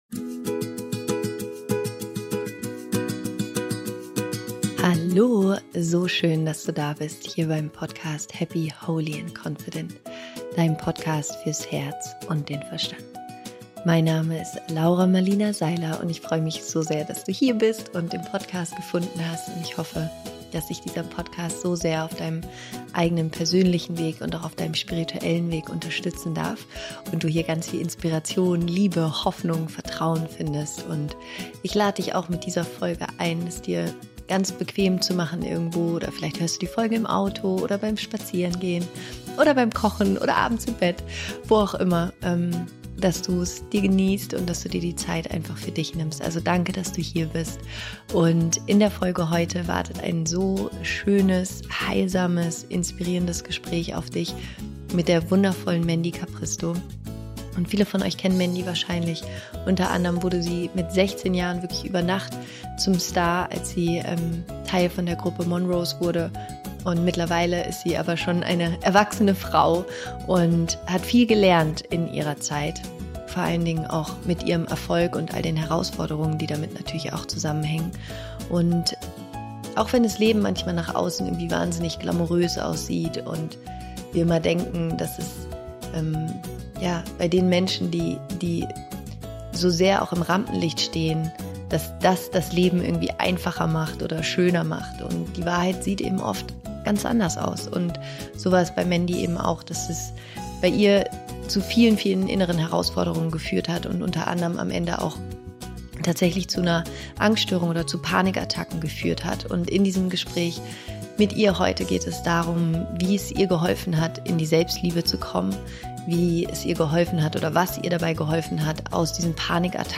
Wie Selbstliebe heilt - Interview mit Mandy Capristo